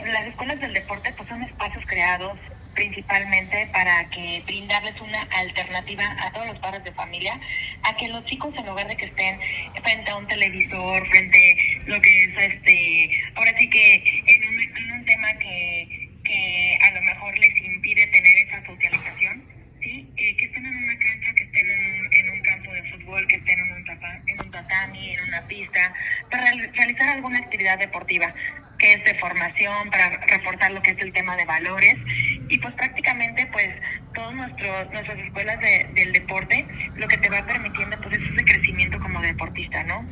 AudioBoletines
Janet Estrada Ponce, Directora de la Comudaj